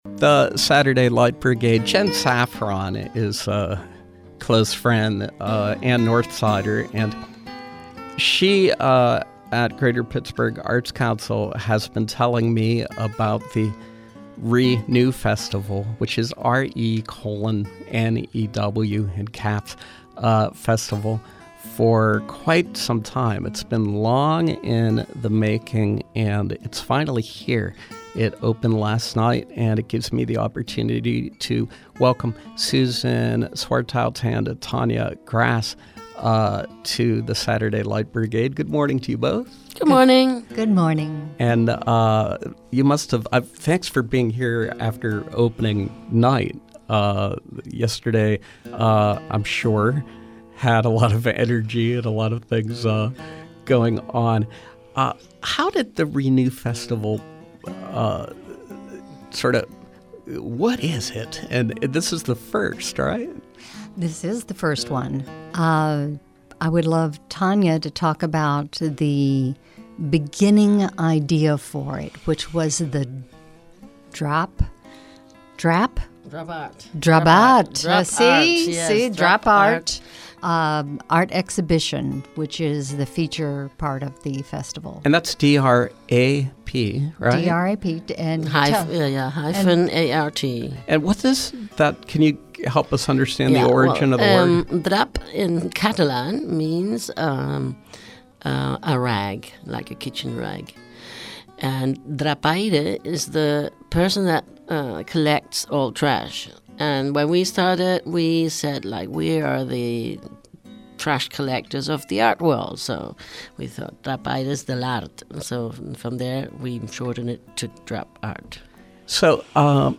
Interview: Re:NEW Festival